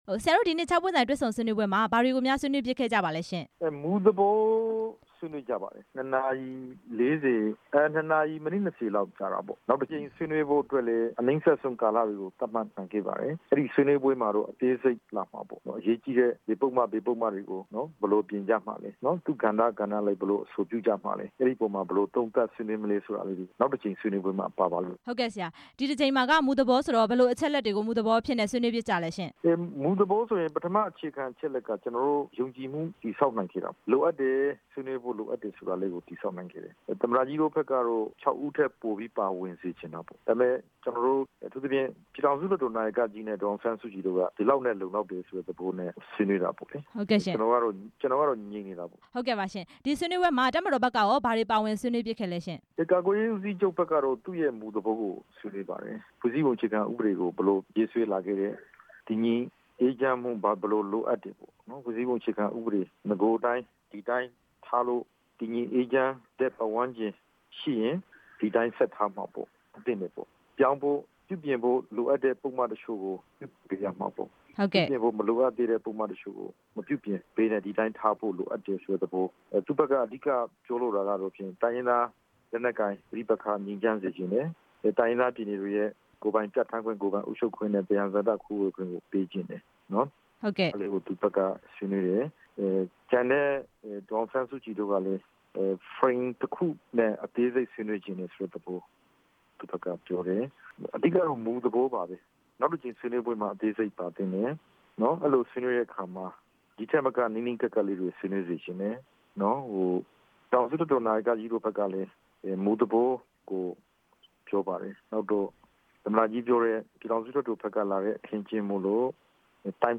ဒေါက်တာအေးမောင်နဲ့ မေးမြန်းချက်